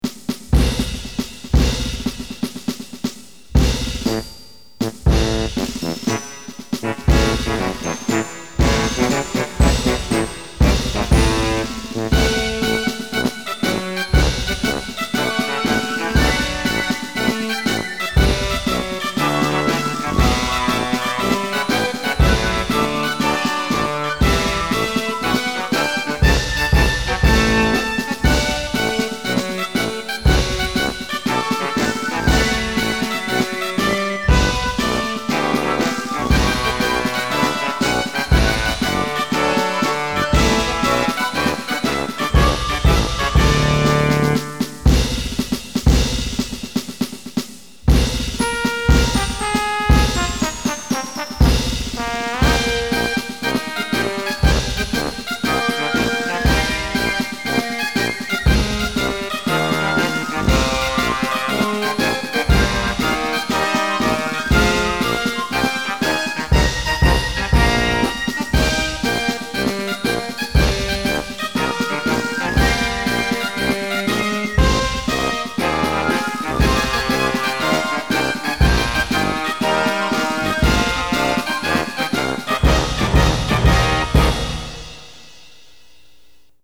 Synthesizer